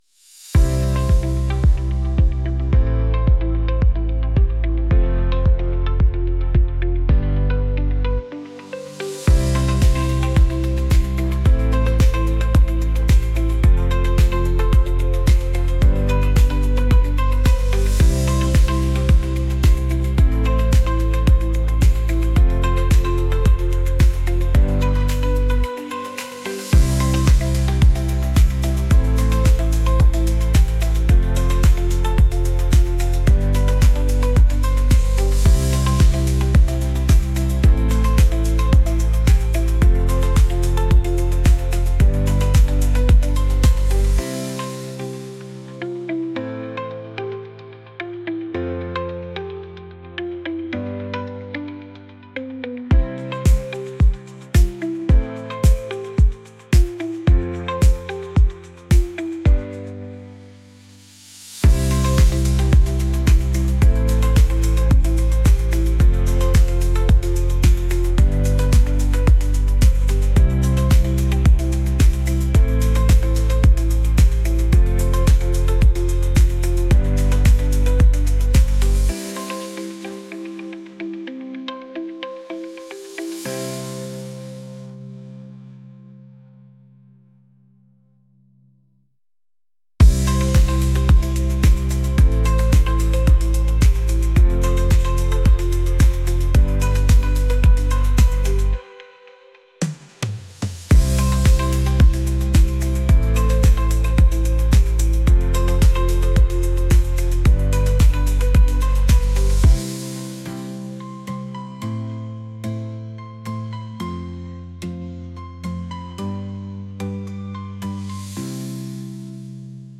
pop | upbeat